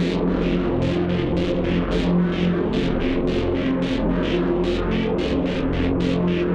Index of /musicradar/dystopian-drone-samples/Tempo Loops/110bpm
DD_TempoDroneC_110-F.wav